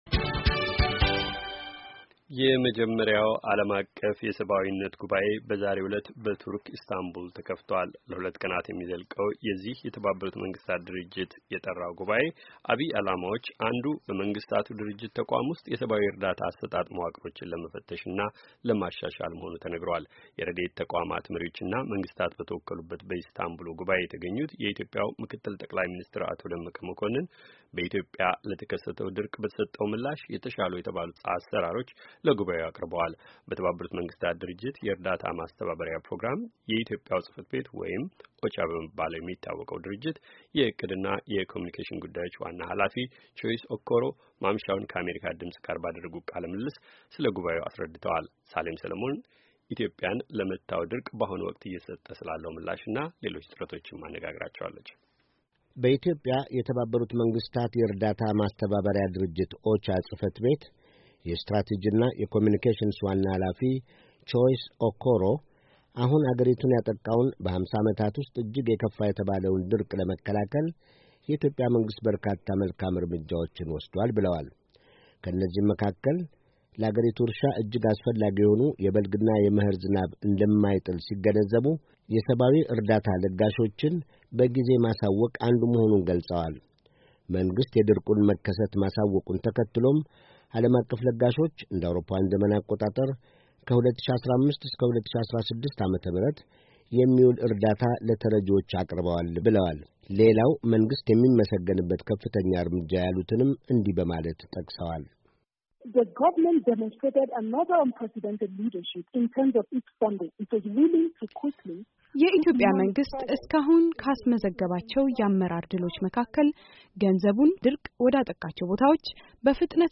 ከአሜሪካ ድምጽ ጋር ባደረጉት ቃለ ምልልስ ስለ ጉባኤው አስረድተዋል።